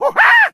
SFX_LOE_022_Death.ogg